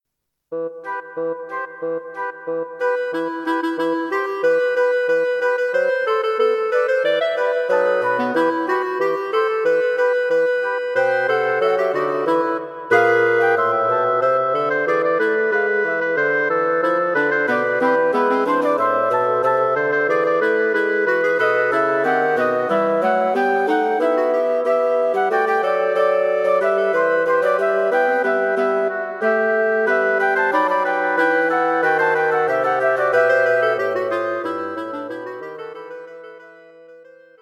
Wind Quartet for Concert performance